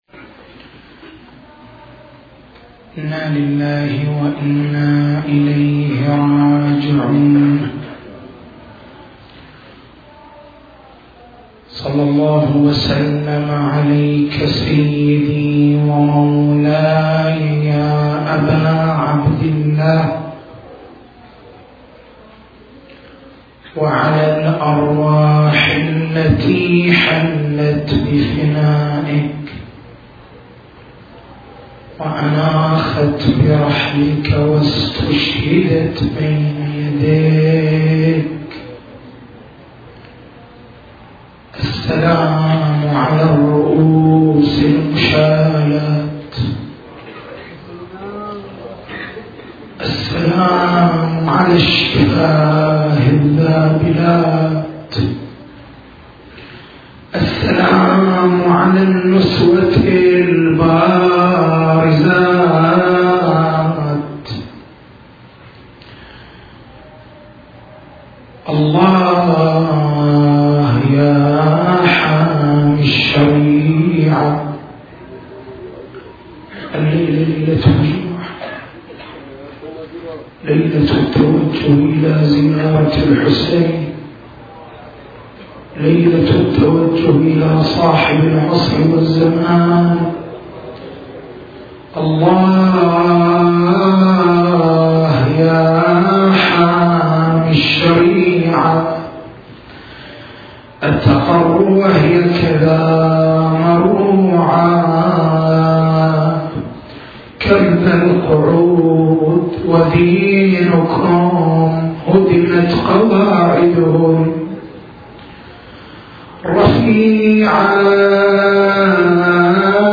تاريخ المحاضرة: 11/01/1427 نقاط البحث: العالمية الجامعية التأثر الكوني شدة المصيبة التسجيل الصوتي: تحميل التسجيل الصوتي: شبكة الضياء > مكتبة المحاضرات > محرم الحرام > محرم الحرام 1427